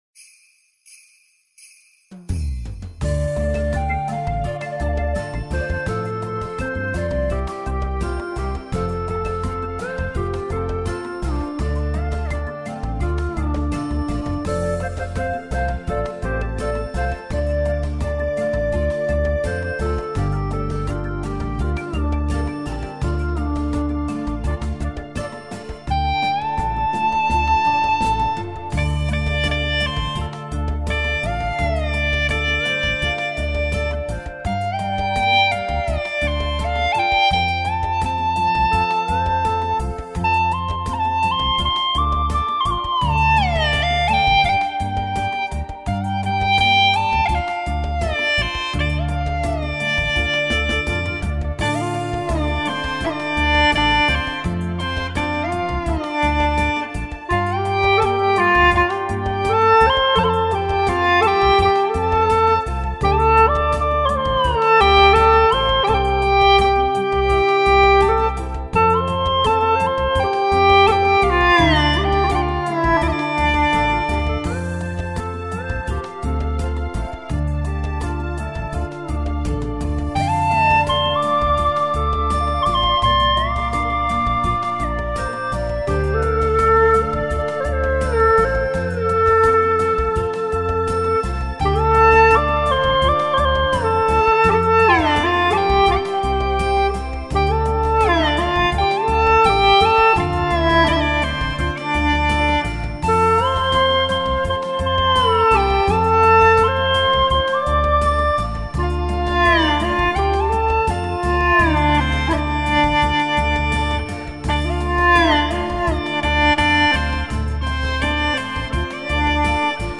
调式 : F 曲类 : 民族